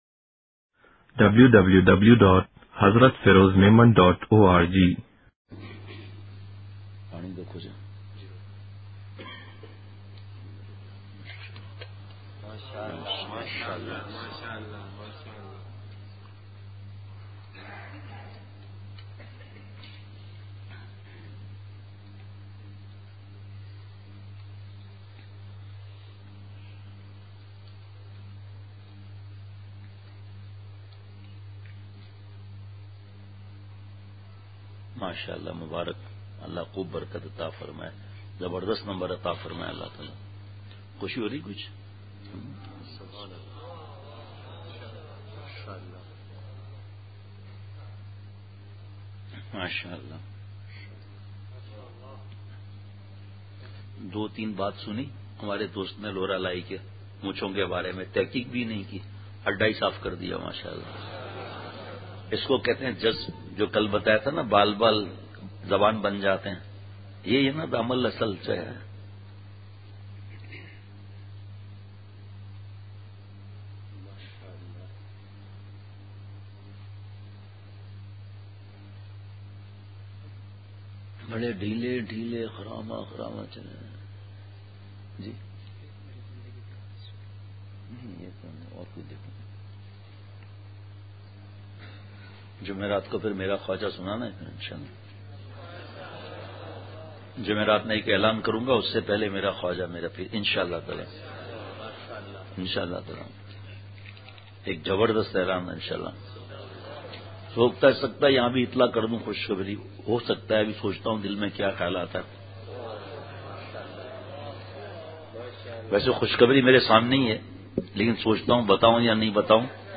مجلس ۶ جنوری ۲۰۱۸ء : طریقت میں کامیابی کی پہلی سیڑھی اپنے شیخ پر کامل اعتماد ہے !